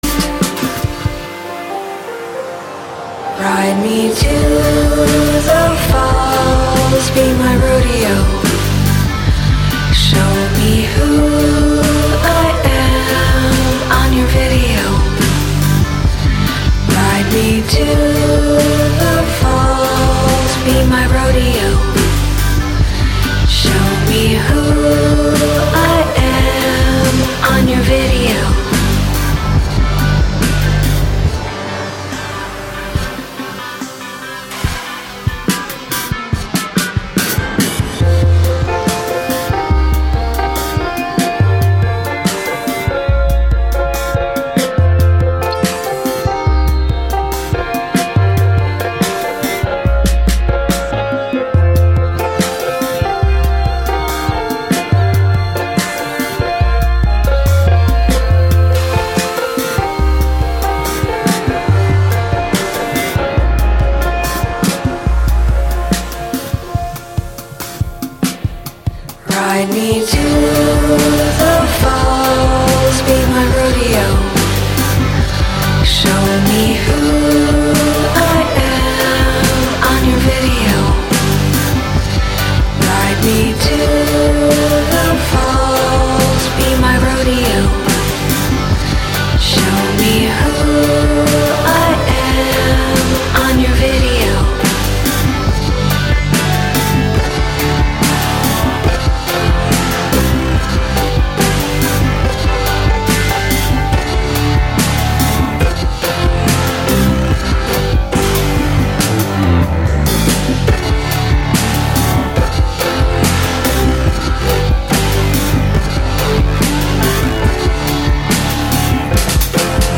sparkling electronica to lightly psychedelic pop